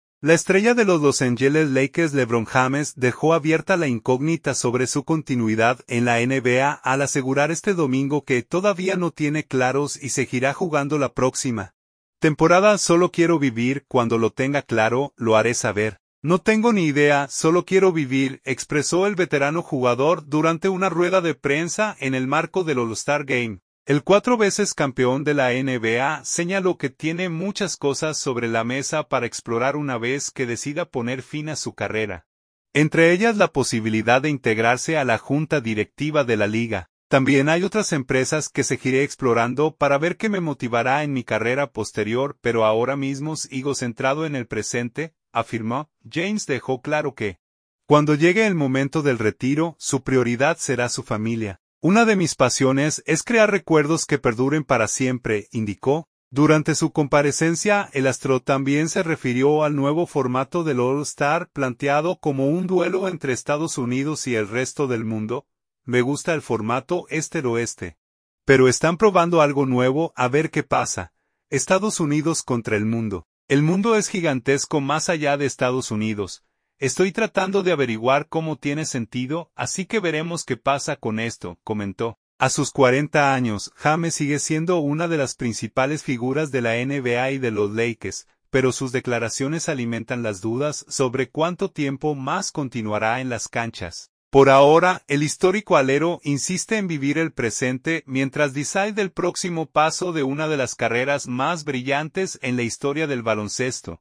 “Solo quiero vivir, cuando lo tenga claro, lo haré saber. No tengo ni idea, solo quiero vivir”, expresó el veterano jugador durante una rueda de prensa en el marco del All-Star Game.